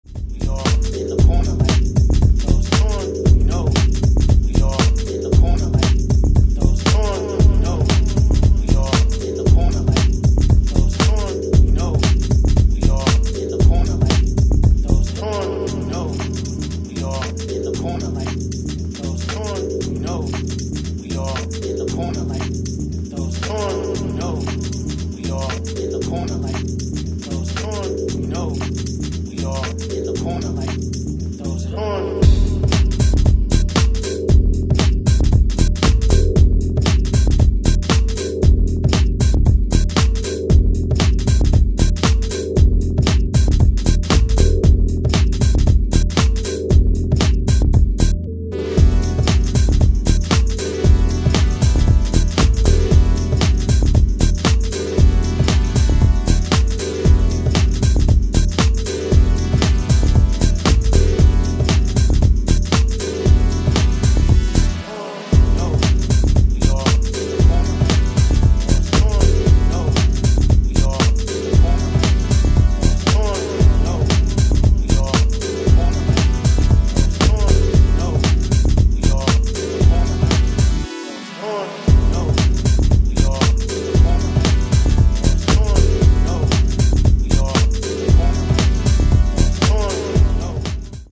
ジャジーでディスコなハウスが全5曲！
ジャンル(スタイル) DISCO HOUSE / DEEP HOUSE